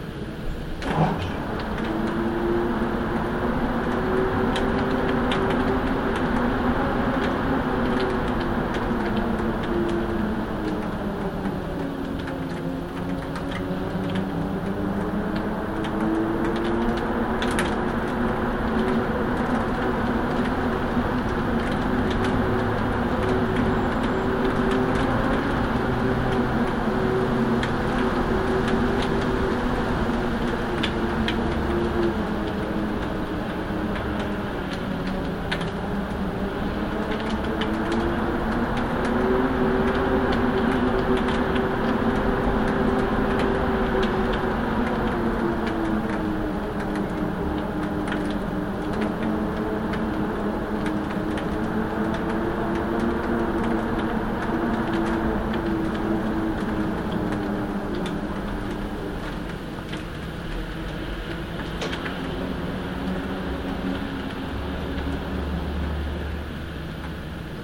Звуки плохой погоды
Свист ветра в ненастную погоду